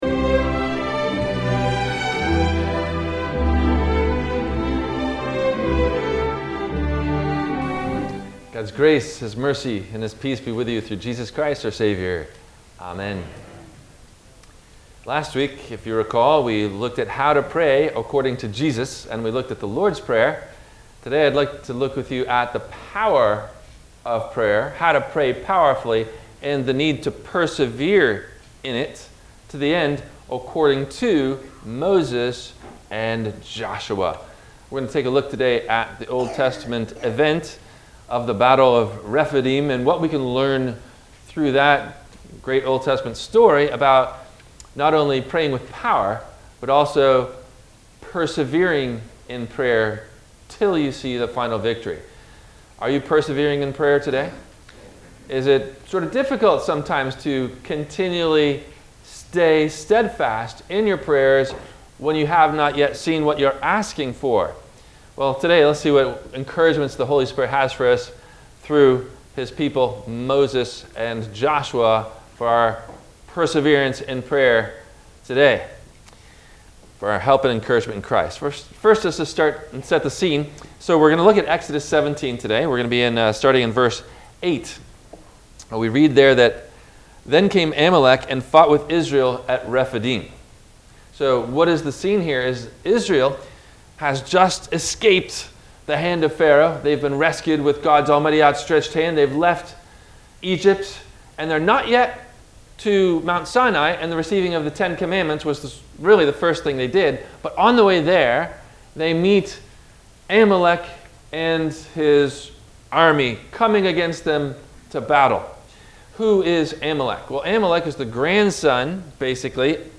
How Should A Christian Woman Treat Her Husband? – WMIE Radio Sermon – October 24 2022